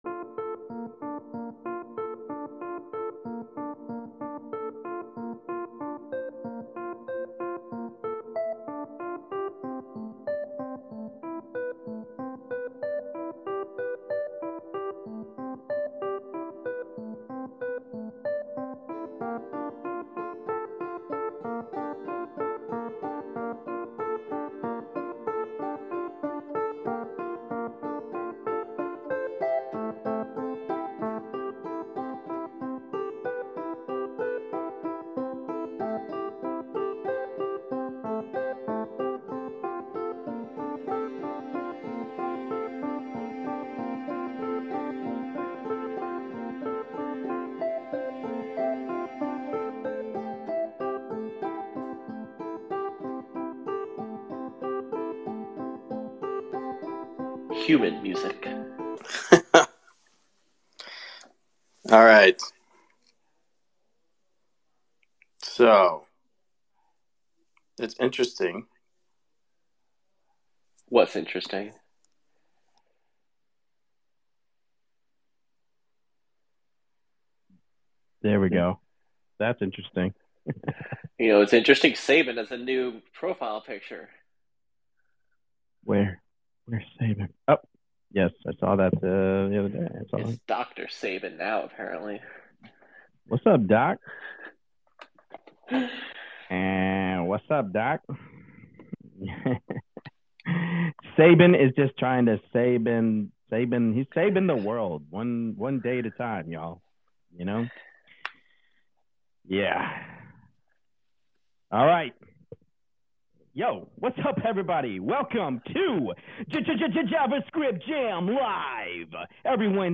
Speakers discuss passwordless web authentication, React performance optimization, and prepping for React Miami in an engaging panel conversation